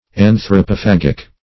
Search Result for " anthropophagic" : The Collaborative International Dictionary of English v.0.48: Anthropophagic \An`thro*po*phag"ic\, Anthropophagical \An`thro*po*phag"ic*al\, a. Relating to cannibalism or anthropophagy.
anthropophagic.mp3